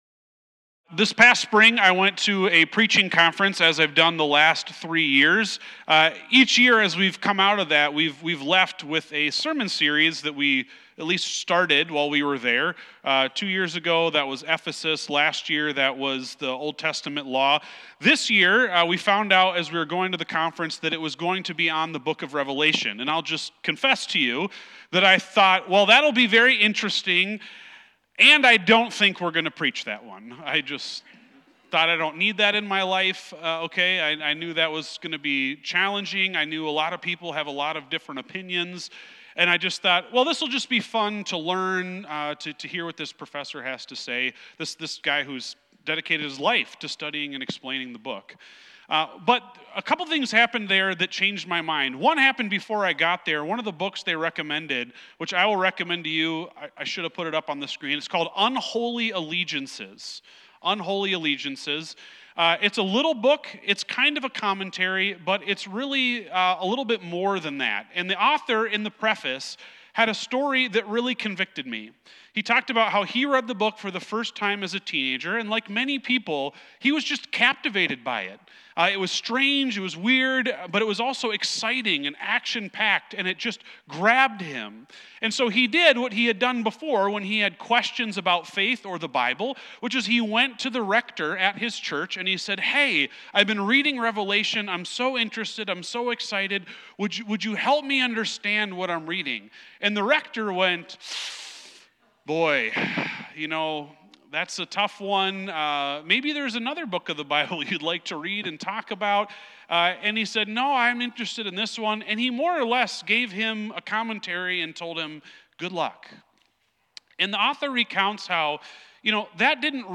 Sermons
sermon-audio-1-4-26.mp3